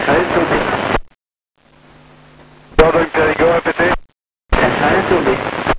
QSO PHONIE avec MIR